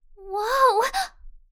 "Woah!" Cute Anime Girl Voice Sound Effect
Category 🗣 Voices
Adorable Anime Anime-Girl Anime-Voice Cute Cute-Anime-Girl Cute-Sound Cute-Voice sound effect free sound royalty free Voices